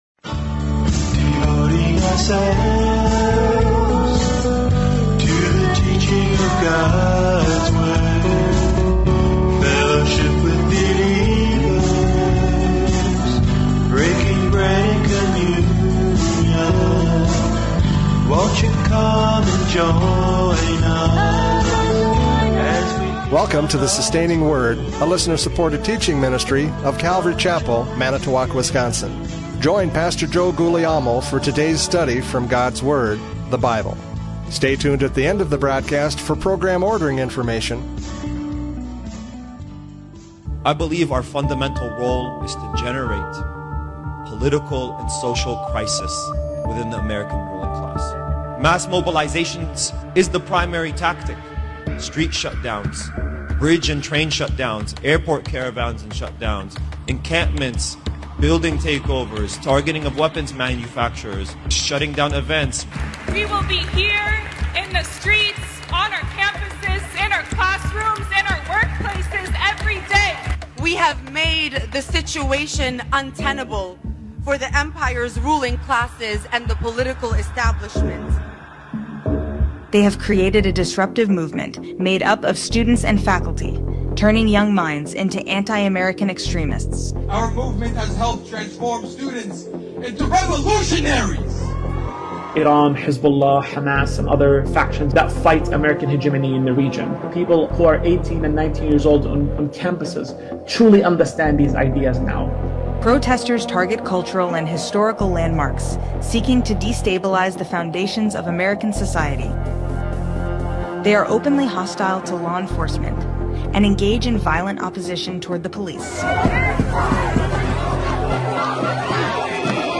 Radio Studies Service Type: Radio Programs « Prophecy Update 2024 Convergence!